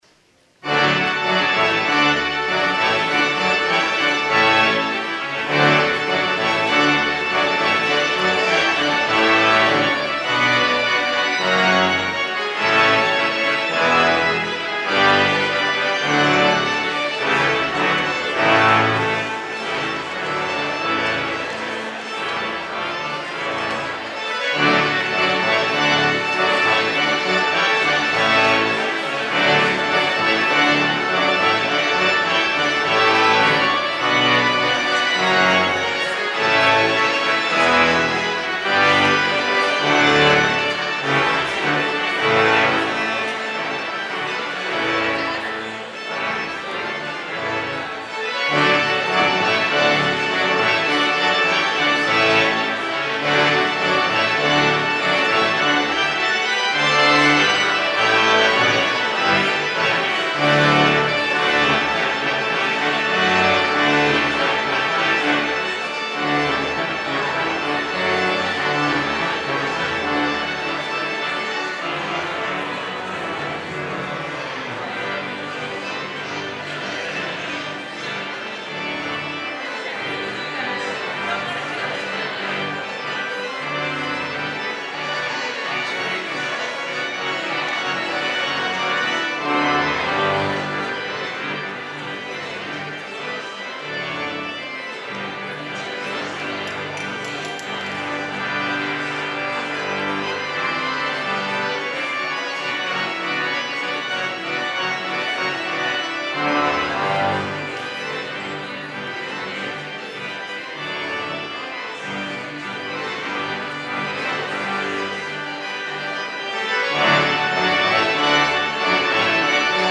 POSTLUDE Fanfare Jacques Lemmens
organ